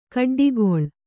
♪ kaḍḍigoḷ